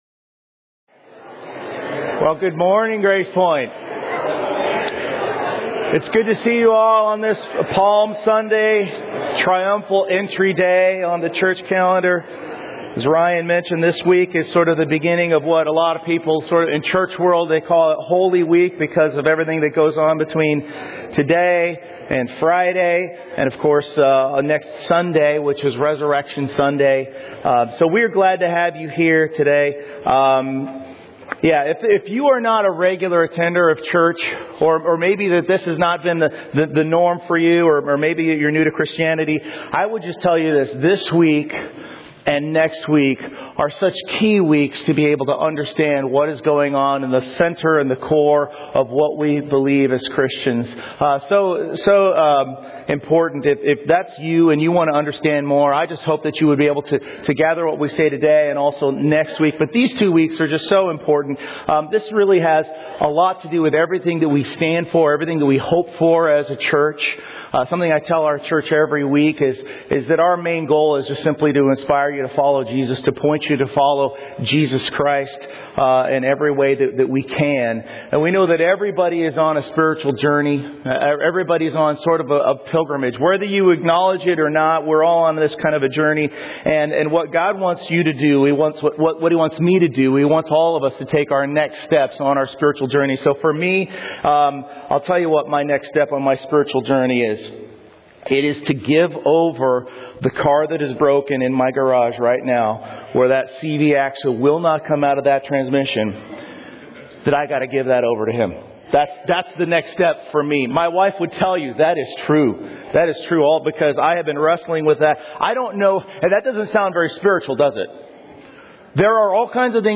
Series: 2025 Sermons